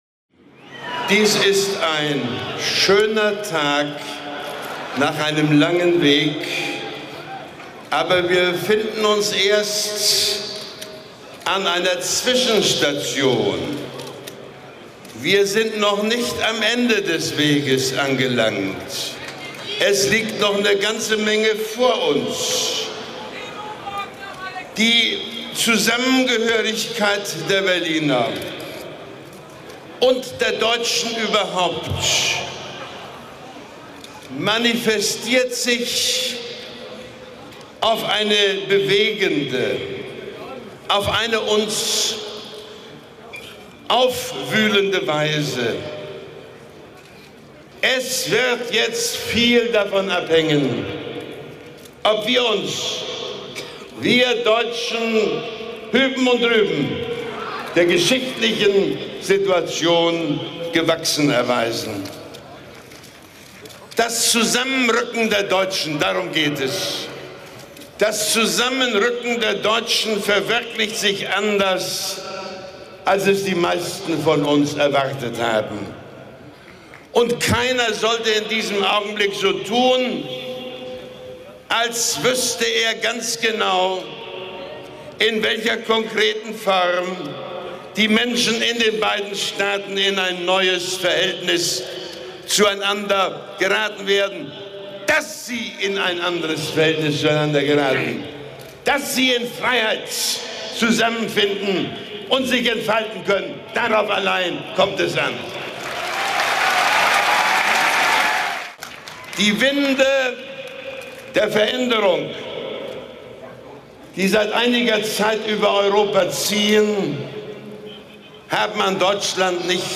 Speech of Willy Brandt on the fall of the Berlin Wall, 10 November 1989
Excerpt from his appearance at the rally in front of Schöneberg Town Hall